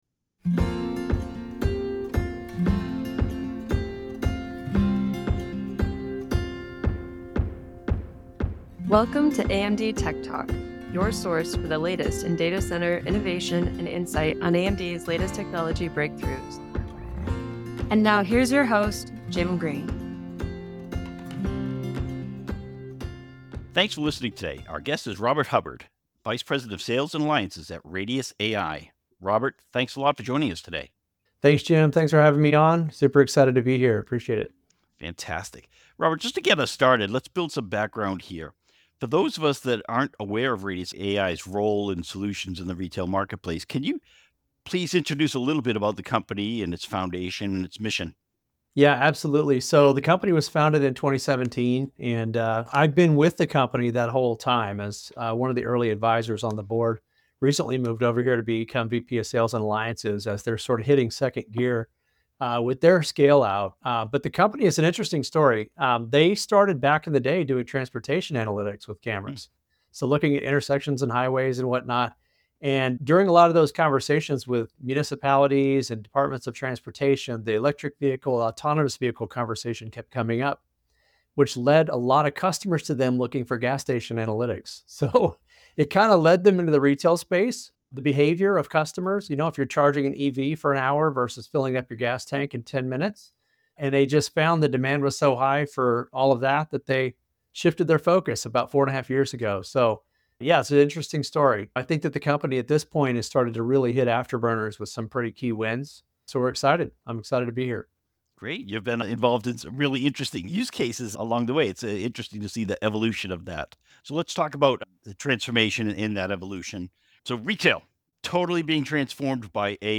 and featuring leading technologists from AMD and the industry, AMD TechTalk features discussion on servers, cloud computing, AI, HPC and more.